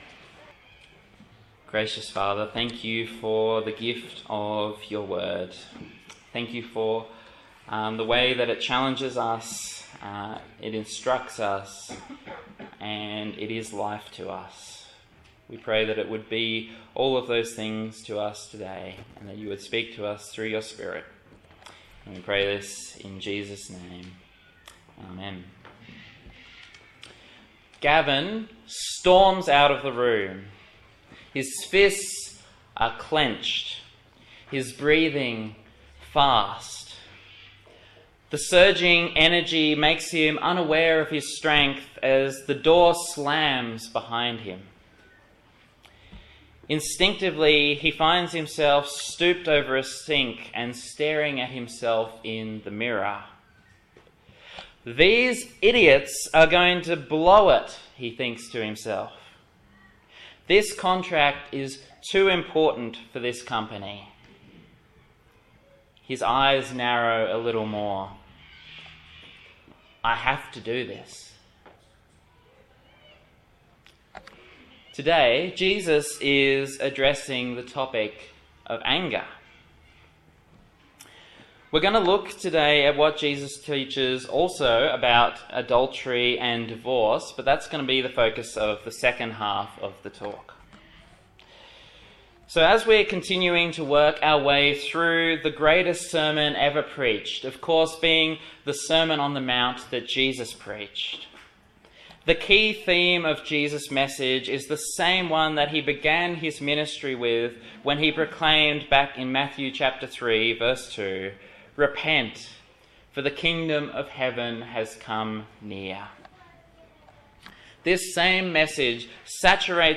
A sermon in the Kingdom Come series on the Gospel of Matthew
Service Type: Sunday Morning